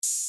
Black Mozart Open Hat.wav